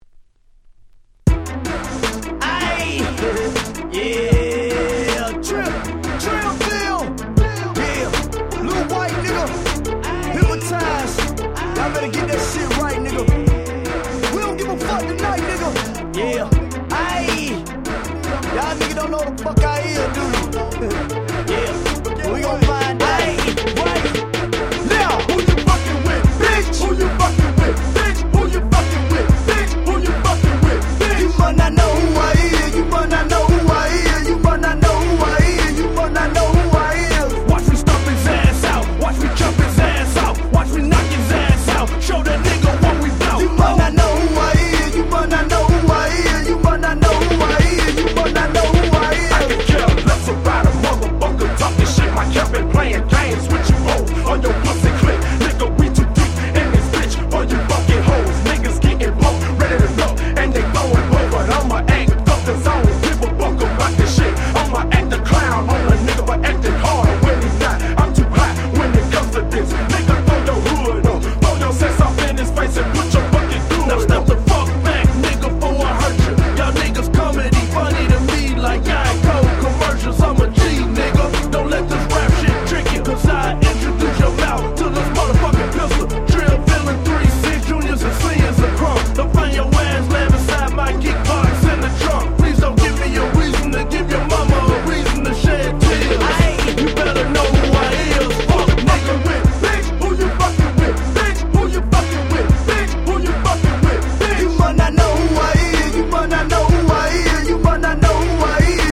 05' Super Nice Southern Hip Hop !!